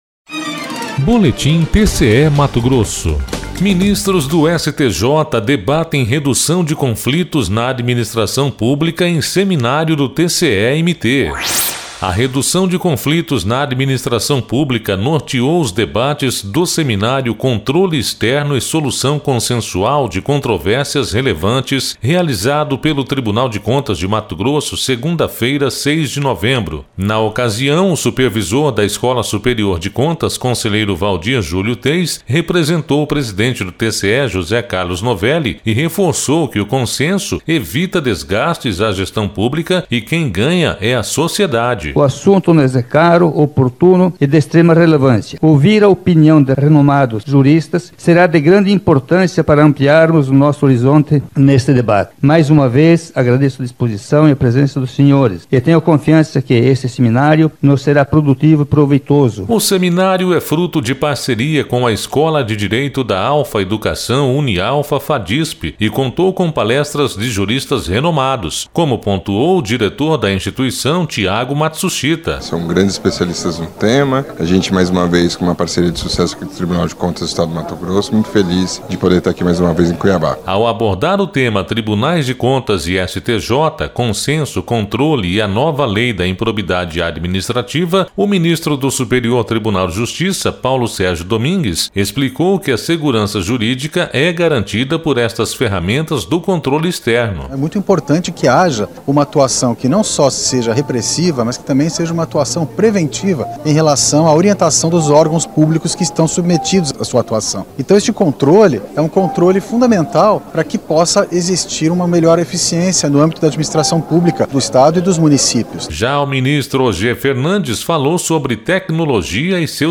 Sonora: Waldir Júlio Teis – conselheiro supervisor da Escola Superior de Contas do TCE-MT
Sonora: Paulo Sérgio Domingues – ministro do Superior Tribunal de Justiça
Sonora: Og Fernandes – ministro do Superior Tribunal de Justiça
Sonora: Sérgio Ricardo – conselheiro do TCE-MT